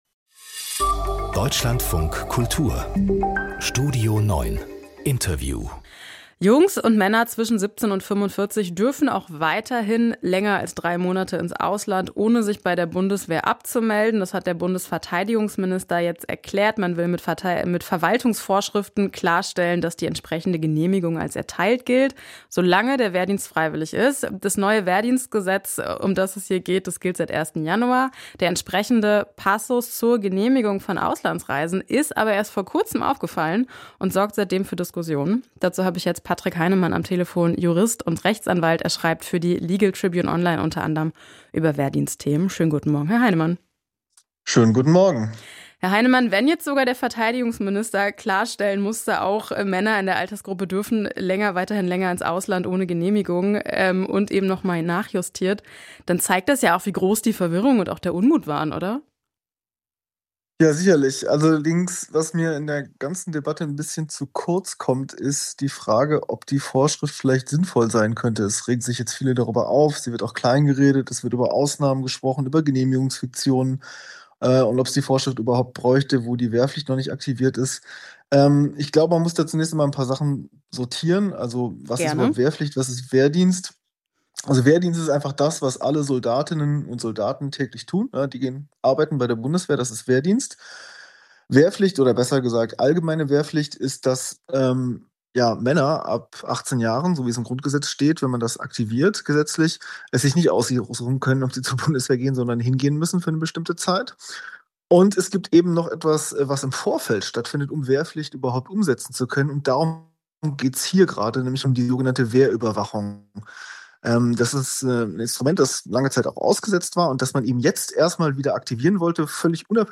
Podcast: Interview